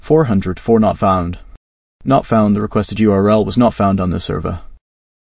value-investors-tts